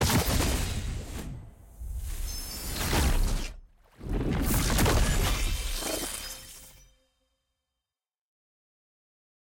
sfx-exalted-rolling-ceremony-multi-gold-anim.ogg